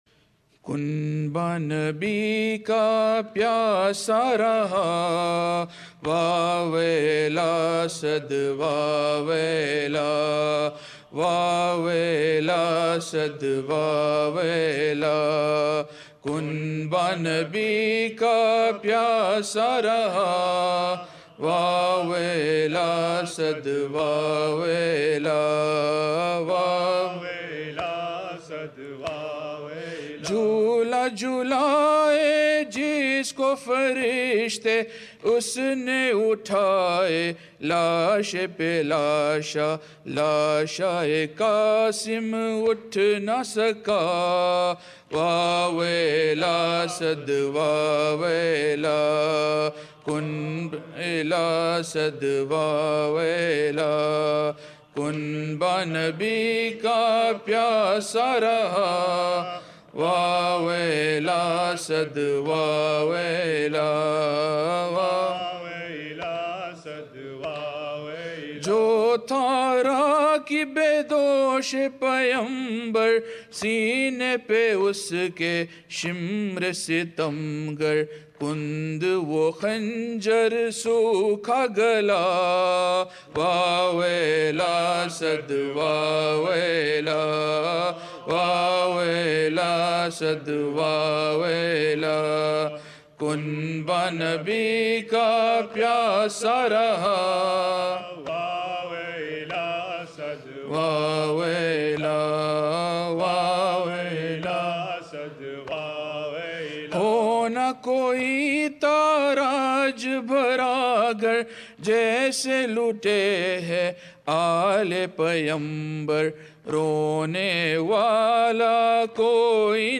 Non Saff Calssic Marsia / Nawha
Anjuman e Sakina